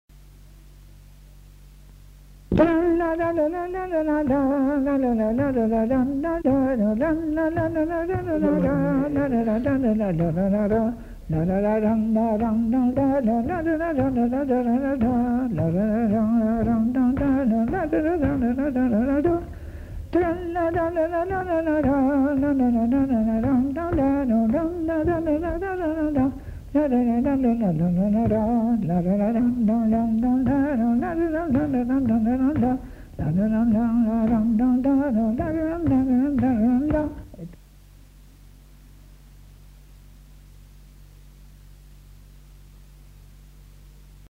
Aire culturelle : Haut-Agenais
Genre : chant
Effectif : 1
Type de voix : voix de femme
Production du son : fredonné
Danse : rondeau